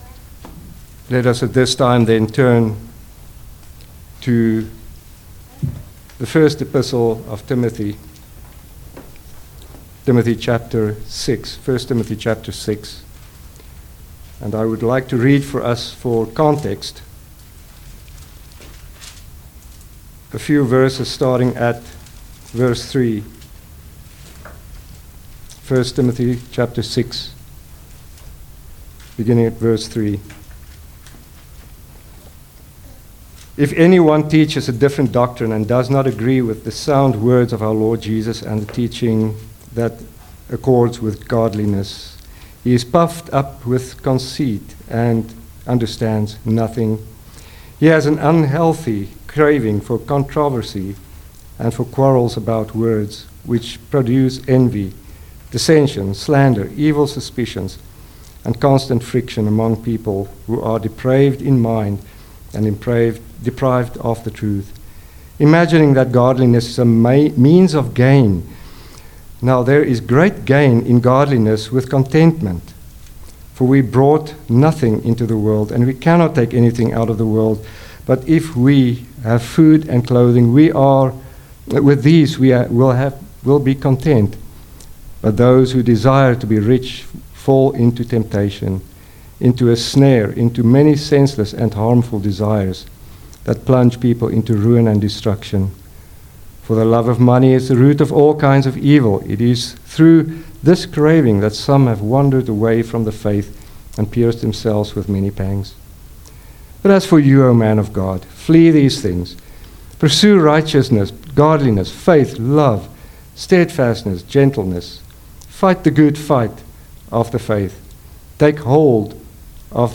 Guest Preachers Passage: 1 Timothy 6:11-12 Service Type: Sunday Evening Service Download the order of worship here .